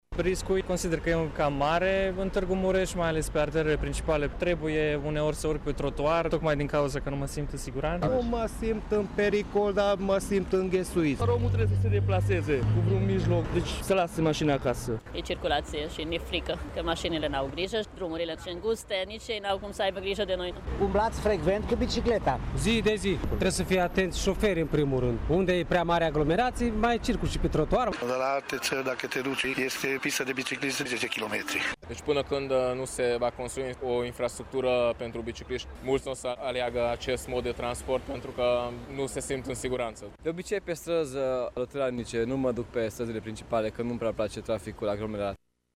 Târgumureșenii care circulă cu bicicleta sunt conștienți de pericolul la care se expun. Aceștia își doresc mai multe piste, dar și zone speciale de parcare: